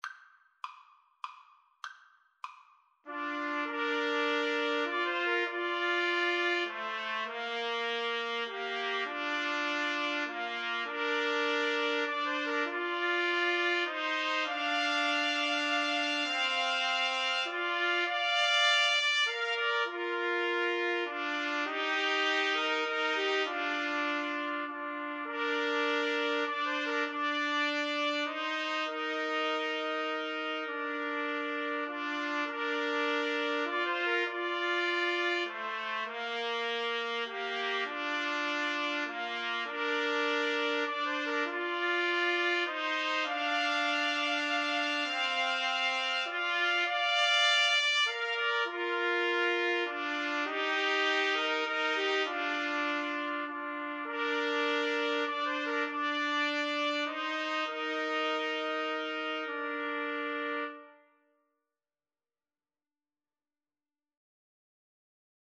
Free Sheet music for Trumpet Trio
Trumpet 1Trumpet 2Trumpet 3
Amazing Grace is a Christian hymn with words written by the English poet and clergyman John Newton (1725–1807), published in 1779.
3/4 (View more 3/4 Music)
Bb major (Sounding Pitch) C major (Trumpet in Bb) (View more Bb major Music for Trumpet Trio )
Trumpet Trio  (View more Easy Trumpet Trio Music)